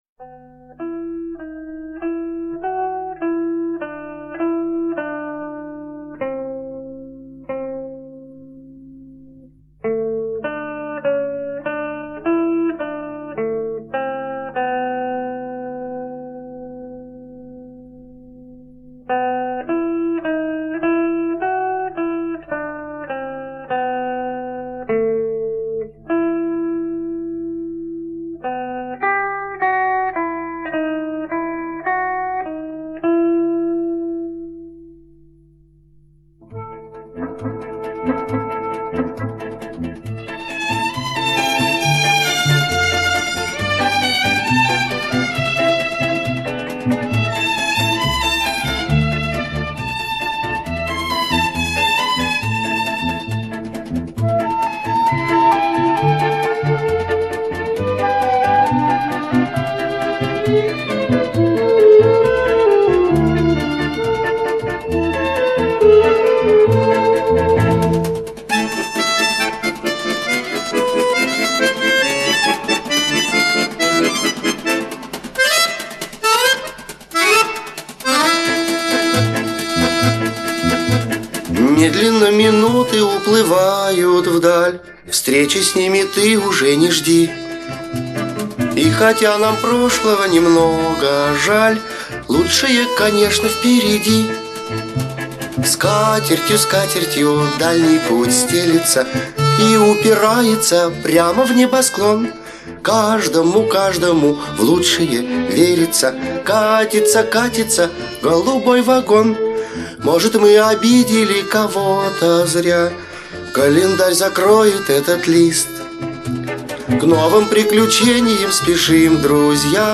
минорная и успокаивающая. Умиротворенный голос